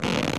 foley_leather_stretch_couch_chair_04.wav